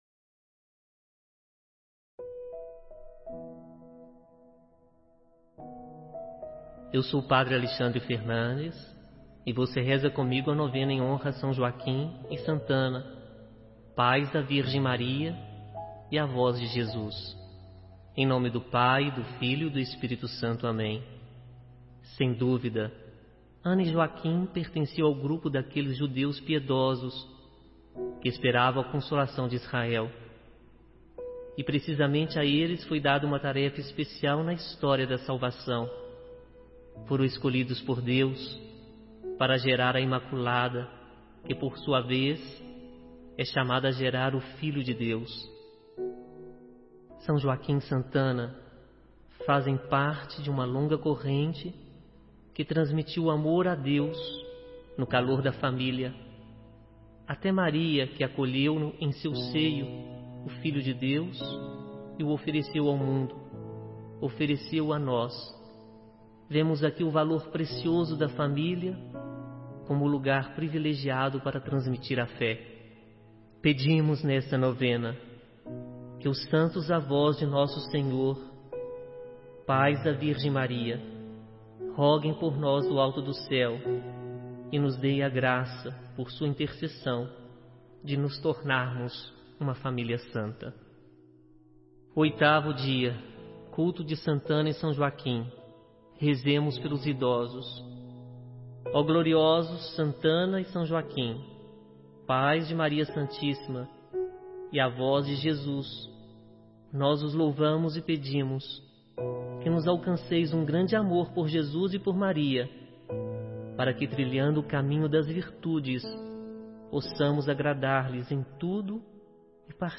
Te convido a rezar comigo a novena em honra a São Joaquim e Santa Ana, pais da Virgem Maria e avós de Jesus.